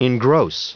Prononciation du mot engross en anglais (fichier audio)
Prononciation du mot : engross